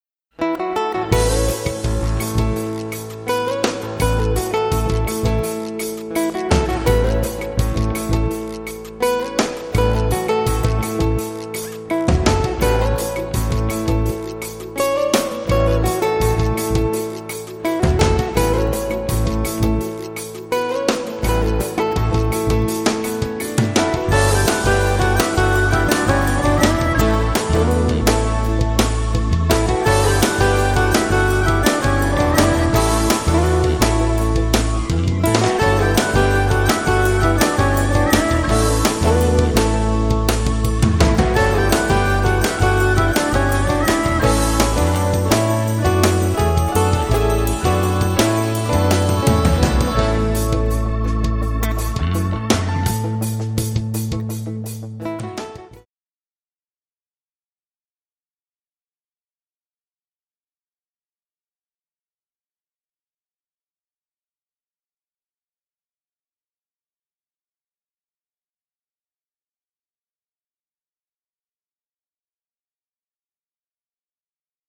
guitar, sax, pan flute and harmonica